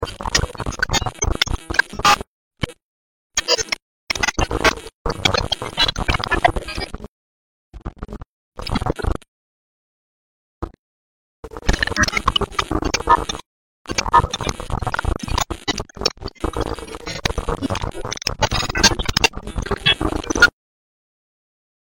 Digital Overlay With SFX! Sound Effects Free Download